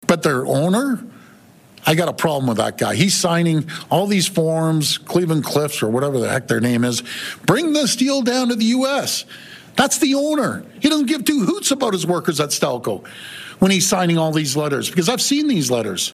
At a press conference in Hamilton on Wednesday, Ford criticized Lourenco Goncalves, president and CEO of Cleveland-Cliffs, after the executive publicly praised U.S. tariffs on steel under President Donald Trump.